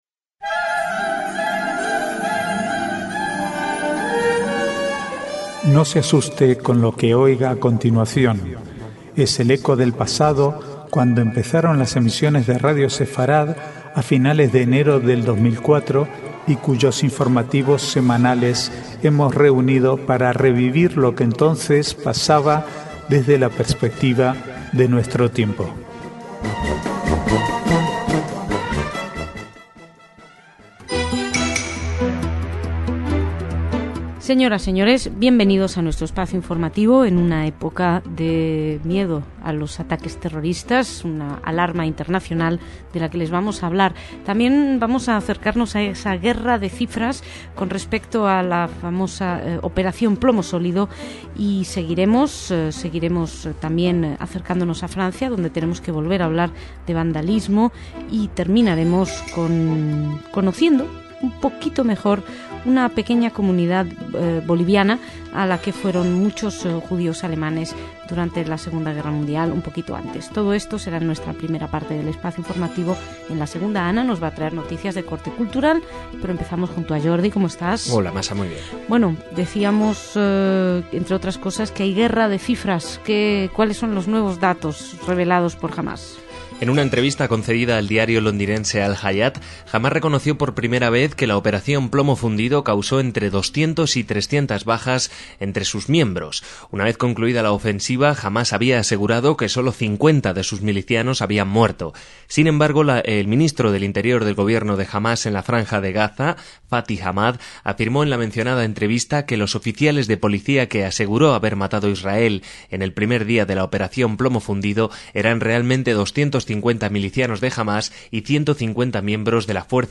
informativos semanales